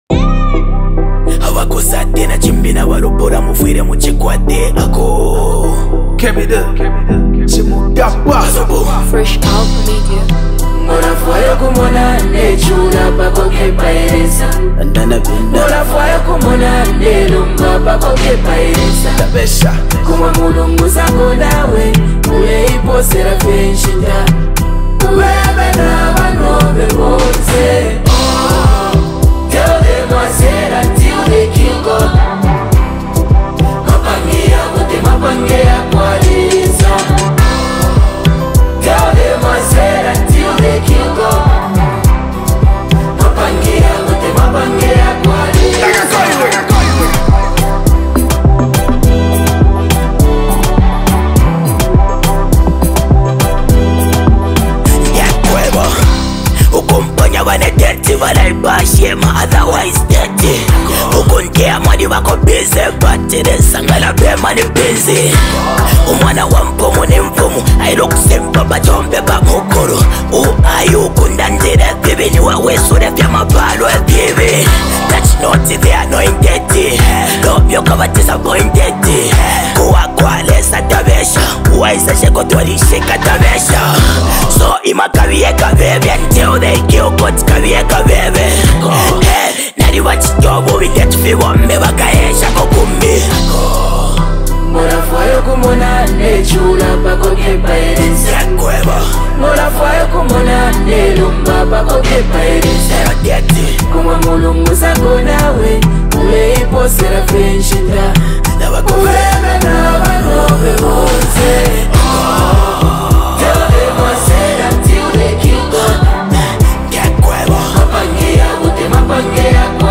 Zambian Music
It’s a track that commands attention from the first beat.